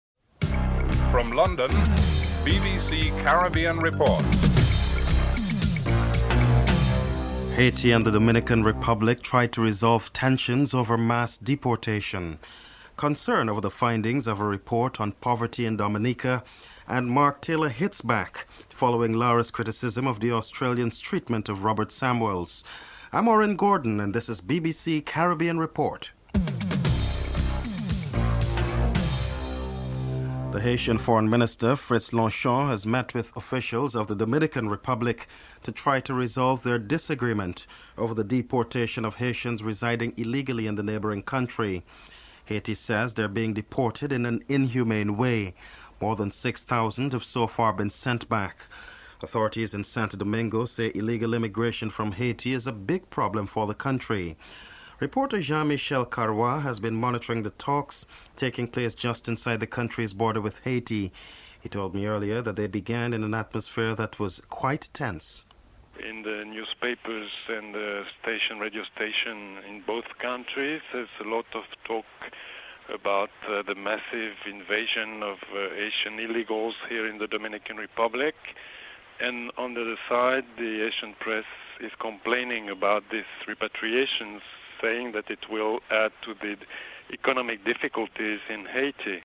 1. Headlines (00:00-00:29)
5. Mark Taylor hits back followilng Lara's criticism of the Australian's treatment of Robert Samuels. Interviews with Australia's Captain, Mark Taylor, Clive Lloyd, Manager of the West Indies Cricket team and Courtney Walsh (11:47-15:28)